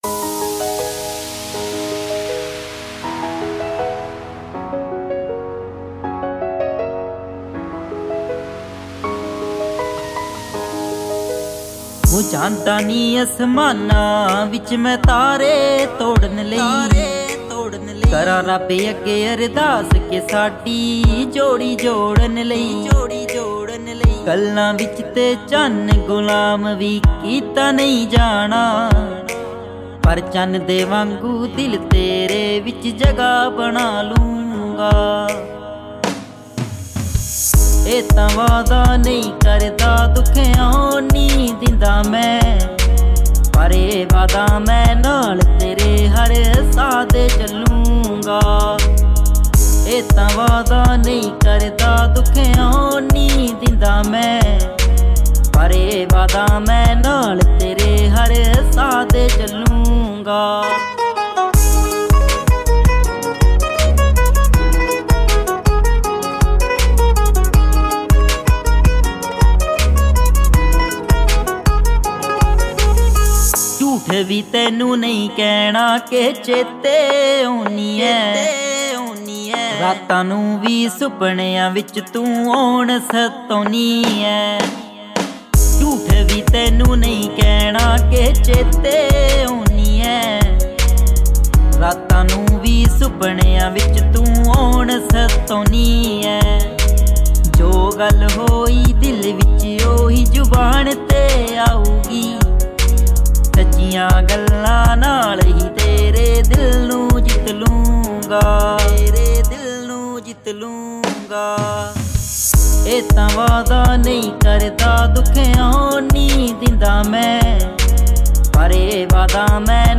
New Song